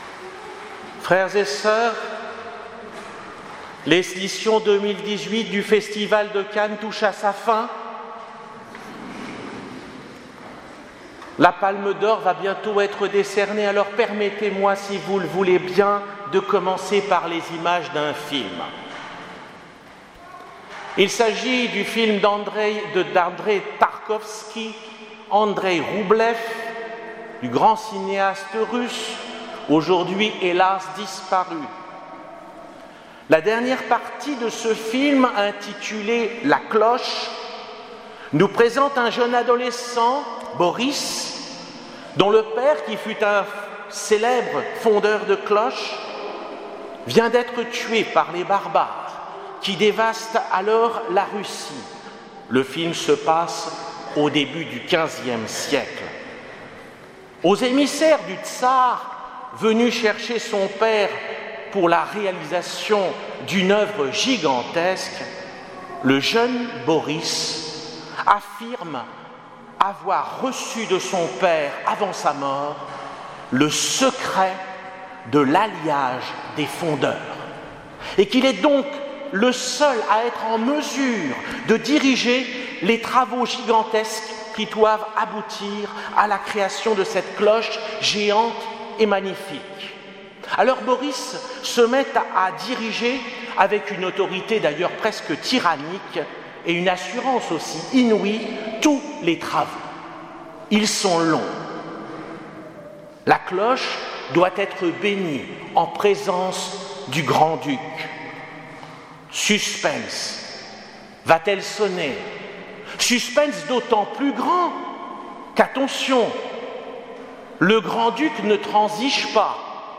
Homélie du dimanche de la Pentecôte 2018